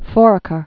(fôrə-kər, fŏr-), Mount